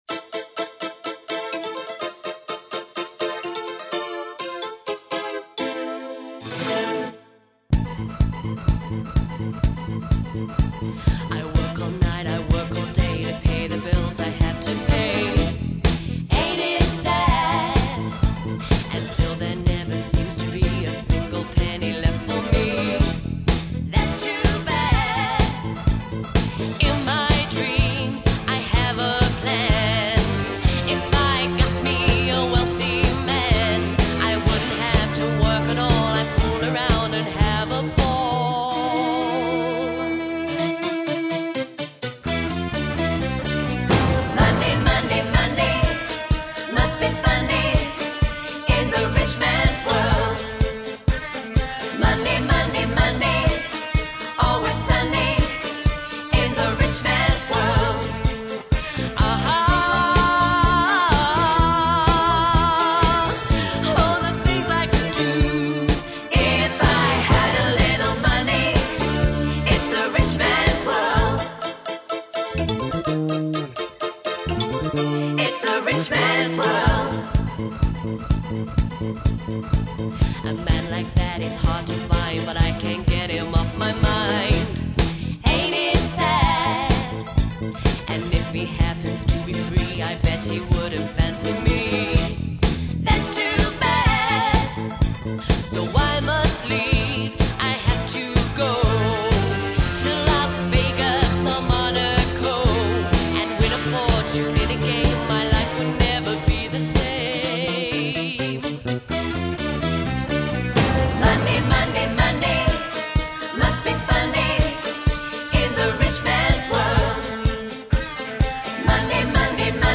a 5 piece live band with 5 part harmonies